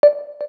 KEYTONE1_2.wav